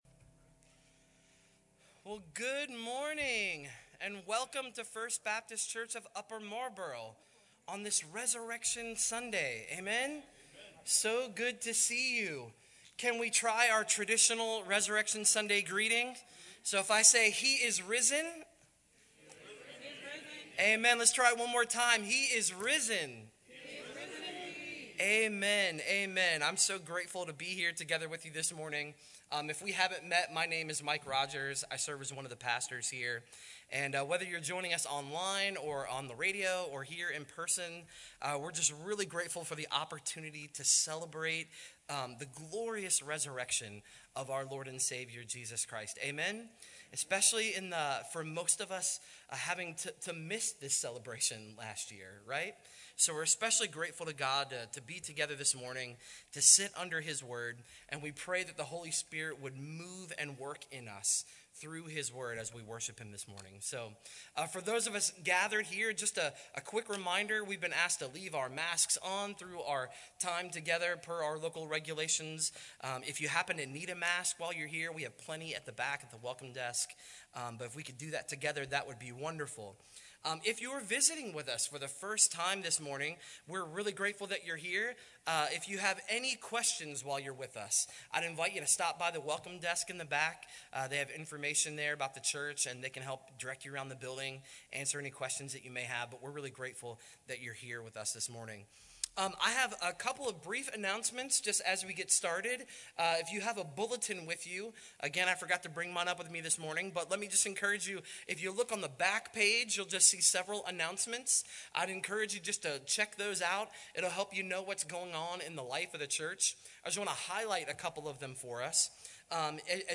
SERVICE-The2Resurrections.mp3